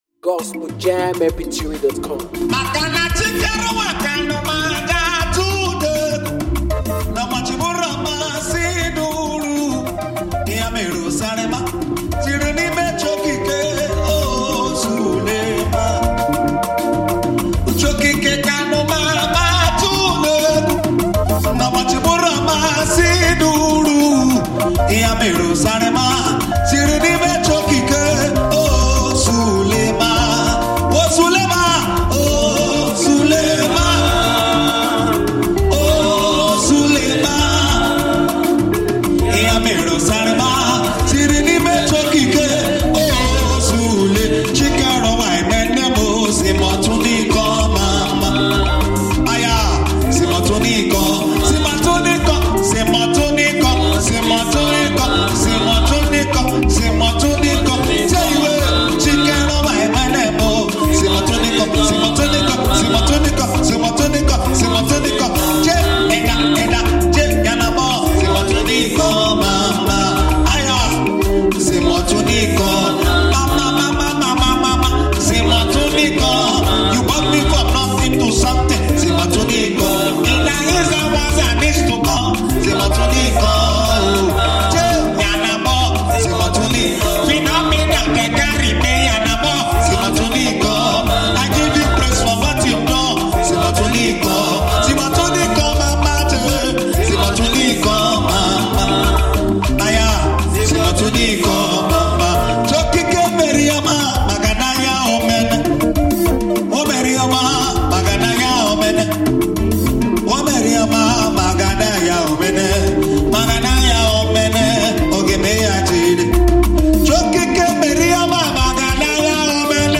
soulful voice